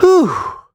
Kibera-Vox_Sigh.wav